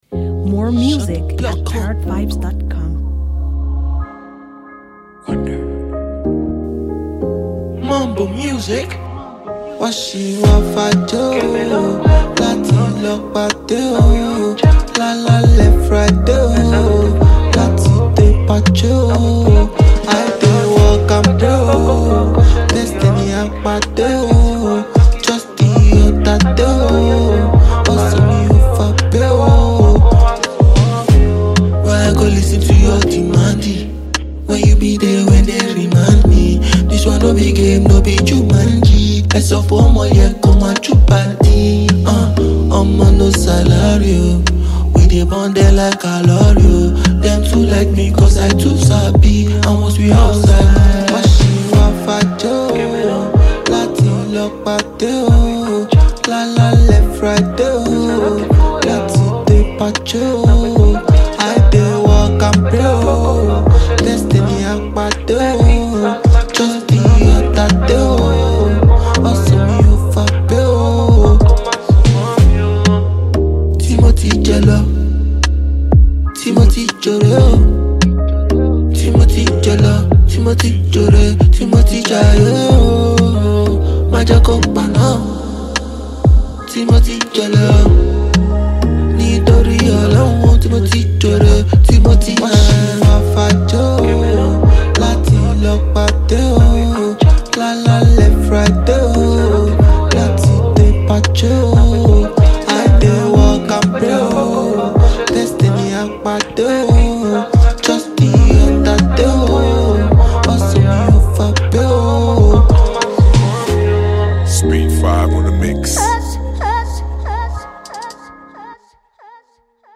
Fast-rising Nigerian singer and songwriter